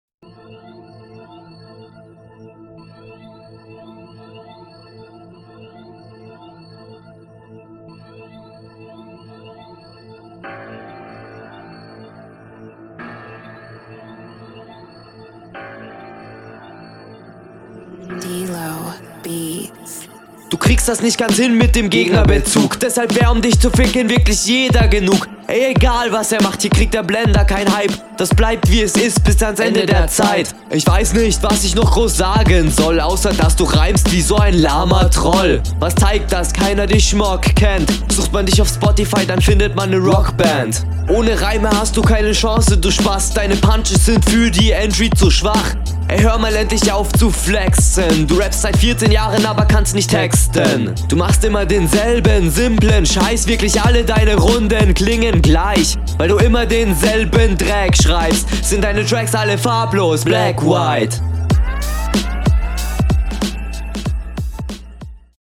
Ich weiß nicht, ich finde auf diesen Beat, kommst du schlechter, als auf den deiner …
Man hört hier raus, dass du nach 2 Zeilen immer nen neuen Take aufnimmst.